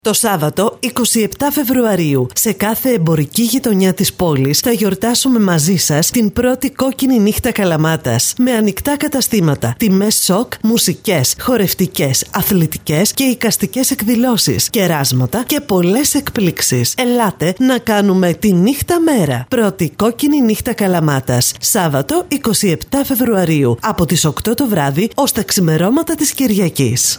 ΚΟΚΚΙΝΗ_ΝΥΧΤΑ_radio_spot_vocal.mp3